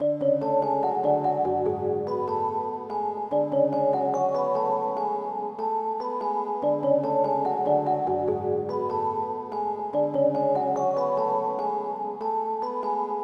钢琴旋律 145bpm
描述：快速的小钢琴旋律，加入了iZotopeVinyl的采样效果。
Tag: 145 bpm Trap Loops Piano Loops 2.23 MB wav Key : G